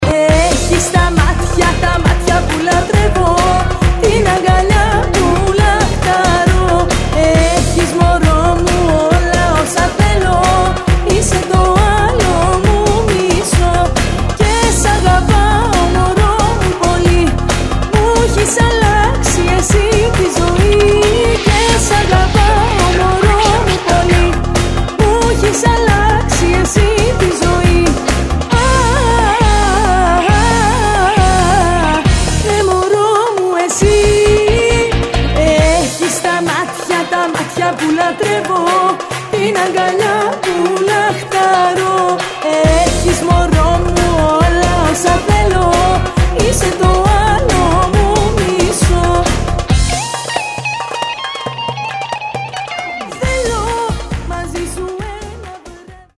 non-stop dance hits